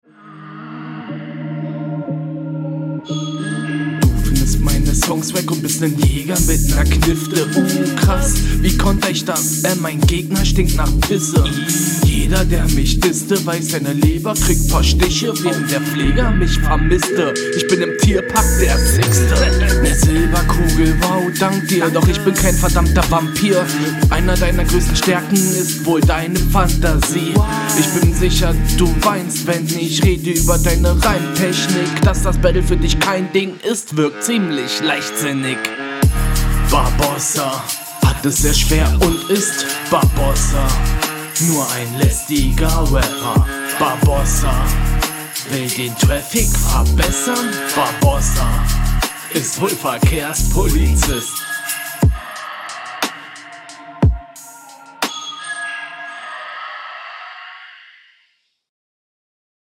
Die Audio ist sehr dünn, drucklos gerappt …
Ich finde du kommst nicht so gut klar auf dem Beat wie dein Gegner.